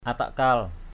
/a-taʔ-ka:l/ (đg.) cầu, khấn = prier. to pray. atakkal lakau Po langik pakah payua atKkL lk~@ _F@ lz{K pkH py&% khẩn xin trời phật độ trì.